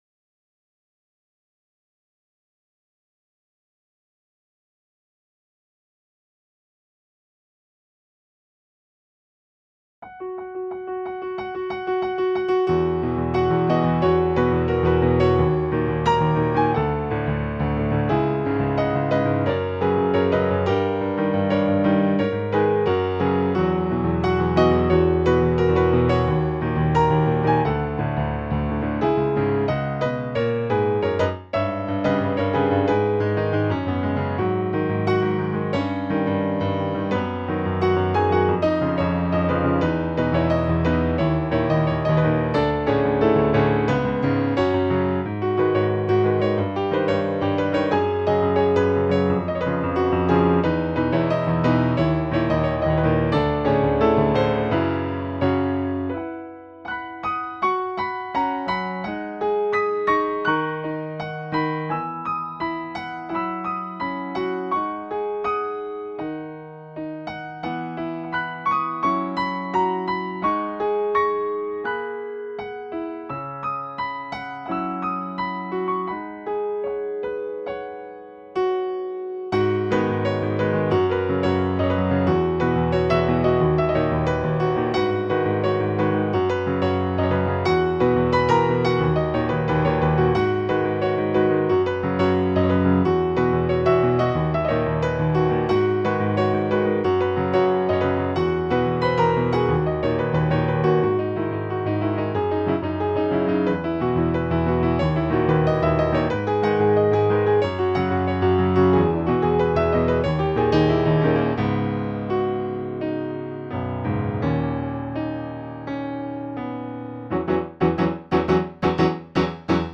Piano cover